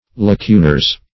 lacunars.mp3